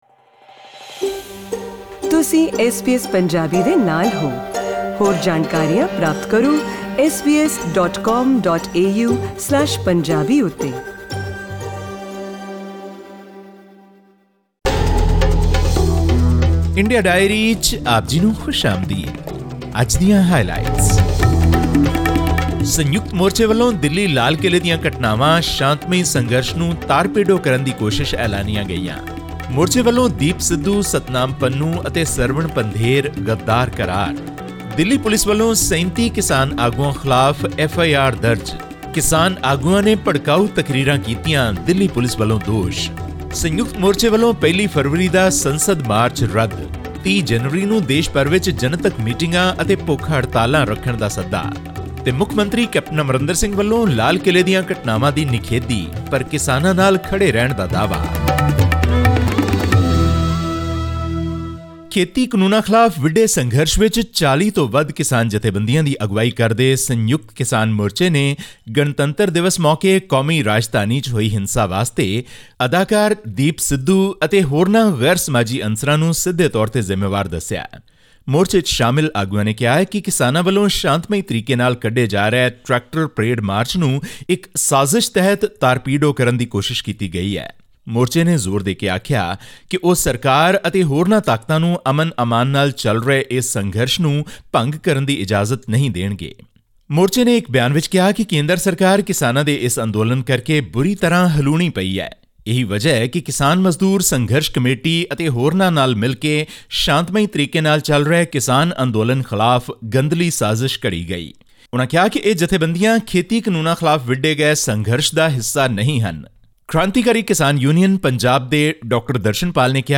Senior farm leaders blamed 36-year-old Punjabi actor-turned-activist Deep Sidhu for inciting violence during the Republic Day tractor rally in New Delhi. This and more in our weekly news segment from India.